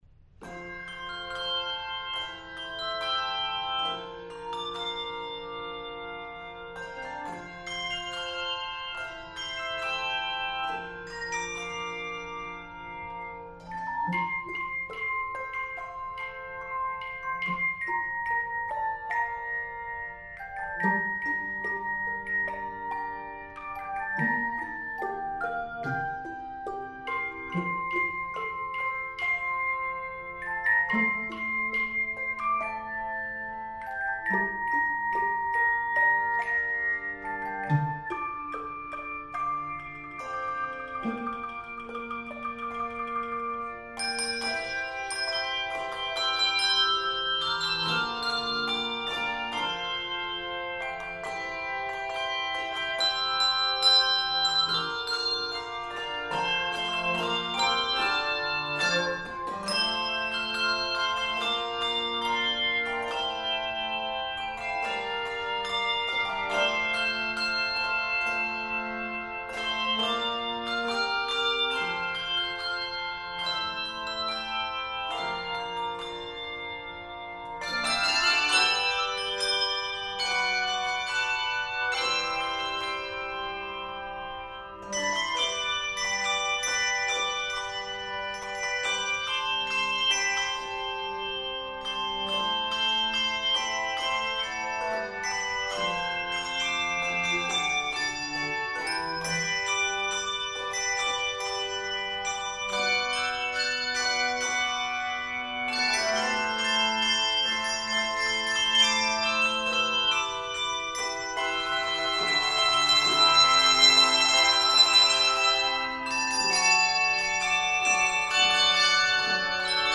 Keys of F Major and G Major.